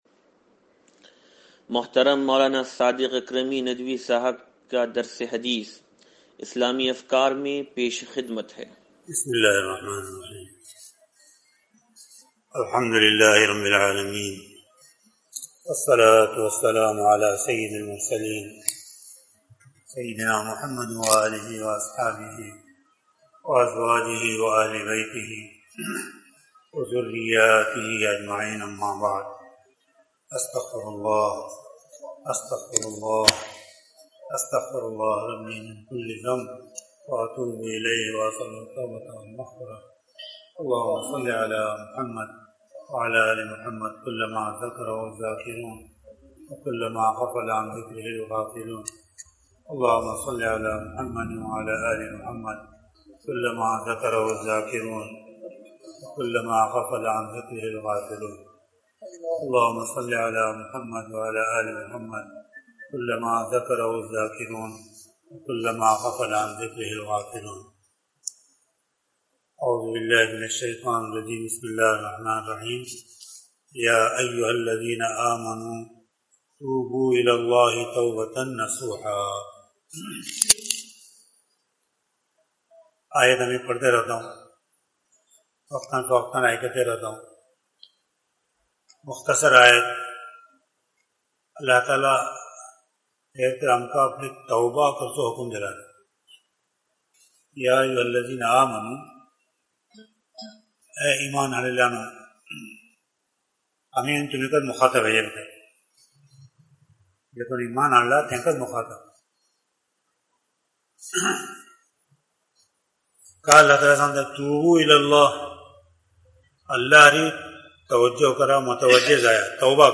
درس حدیث نمبر 0502